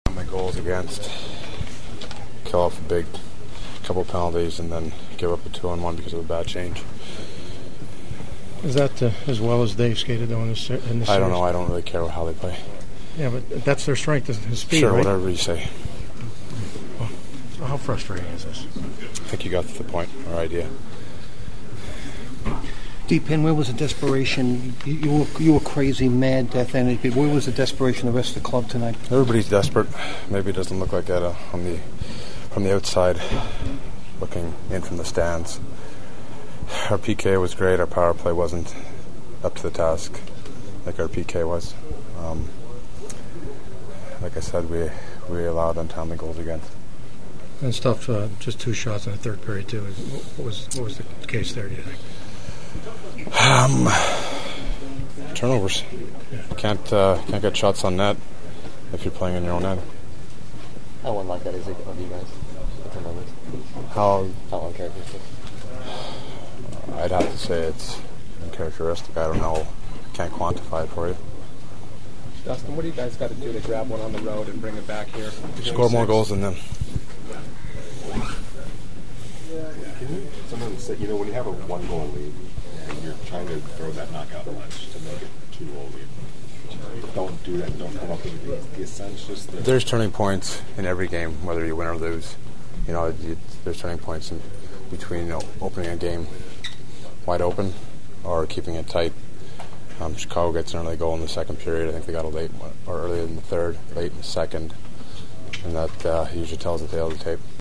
The following are my sounds of the postgame and this one was far from fun to get the losing side to have to comment on this game and what lies ahead…which immediately is a potential season-ending game 5 in Chicago on Saturday.
A very upset (as you’ll hear) Kings RW Dustin Penner: